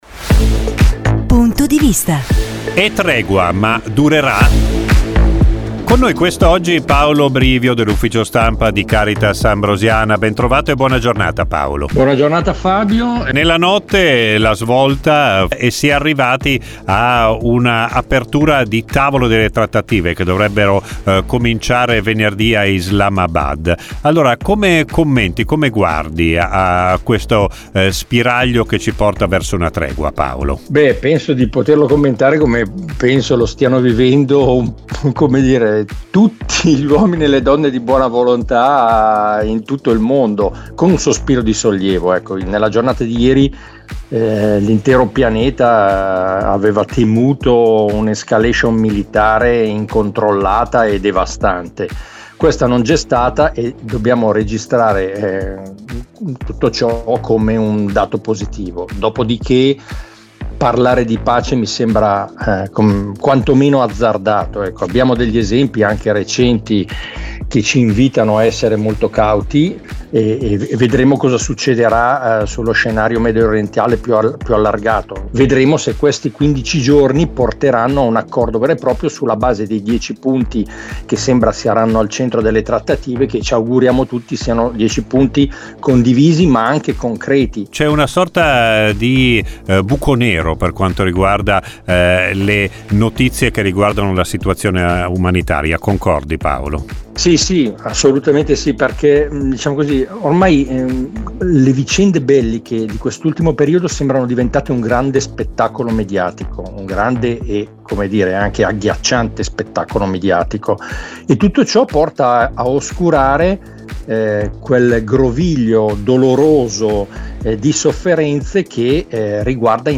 ANALISI